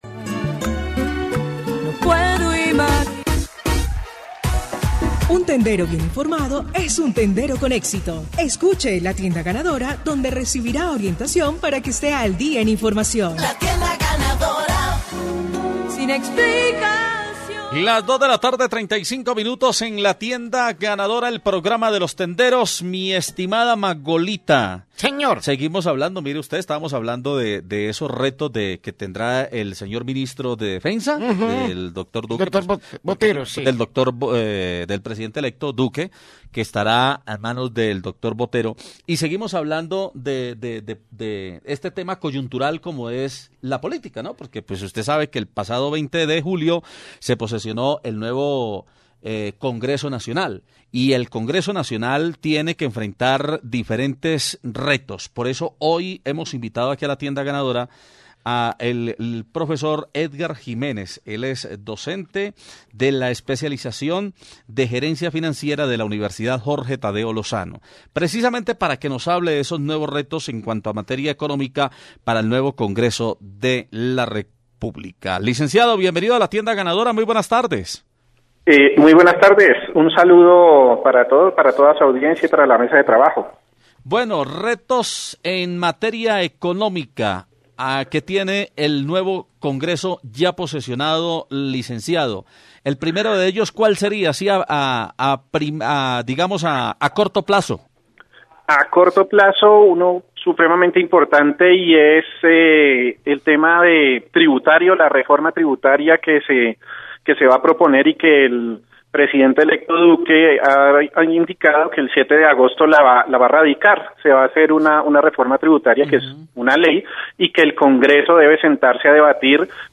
entrevista_u_tadeo_economica.mp3